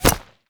bullet_impact_rock_05.wav